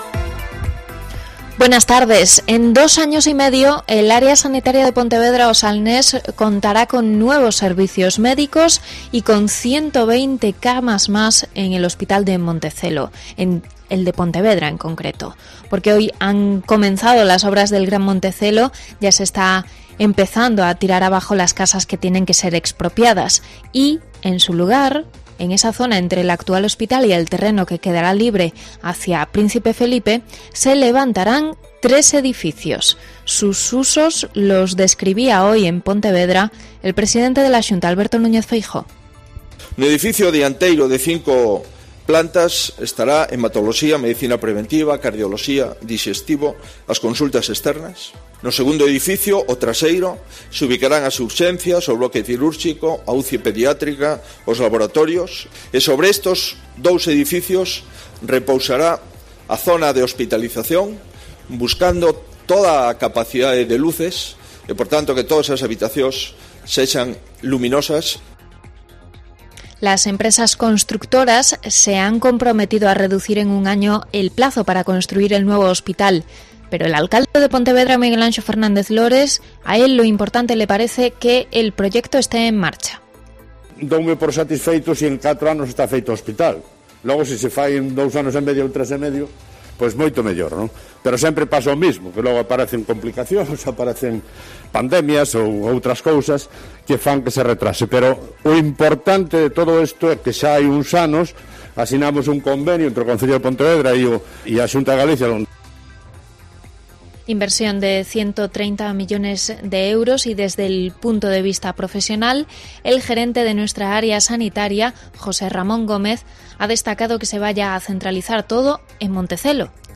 Mediodía COPE Pontevedra y COPE Ría de Arosa (Informativo 14:20h)